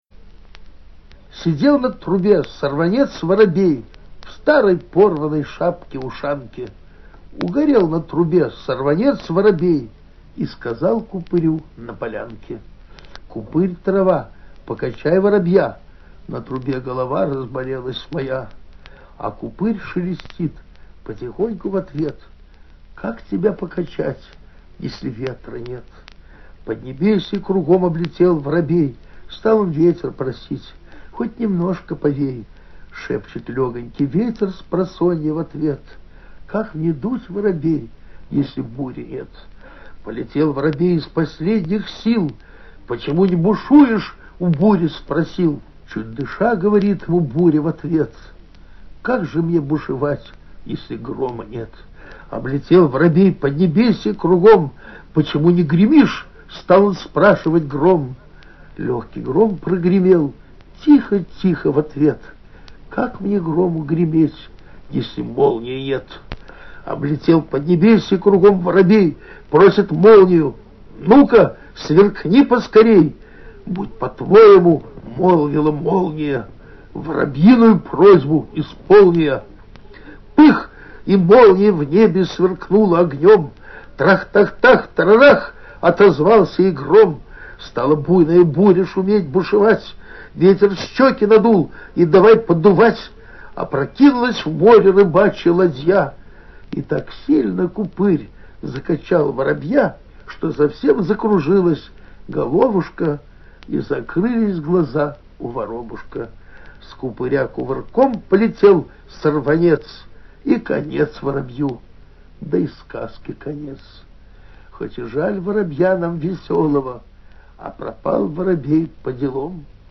Yakovlevich-Marshak-Kto-vyzval-buryu-chitaet-avtor-stih-club-ru.mp3